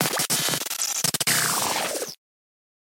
glitch_death.mp3